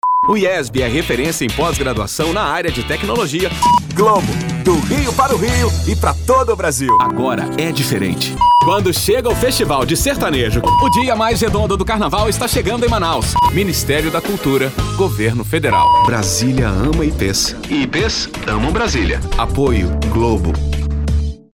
A natural and versatile voice without vices or exaggerations, neutral accent to perform many different styles characters and ages, always giving the right approach to engage the audience.
Sprechprobe: Werbung (Muttersprache):